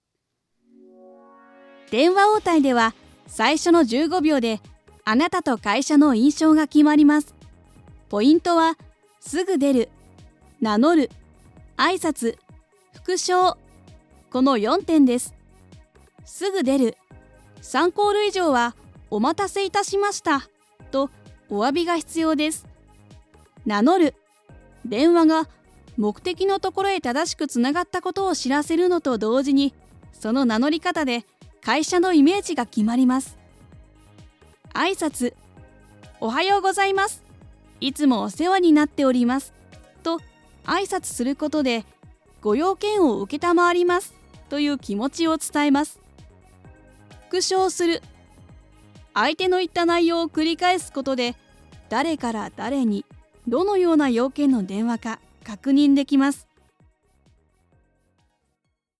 中性的で響く声を武器に、アニメーションやキャラクターナレーションをはじめ、吹き替えや演技まで幅広いジャンルに対応。
Voice Sample
【Eラーニング】電話応対
voicesample_elearning.mp3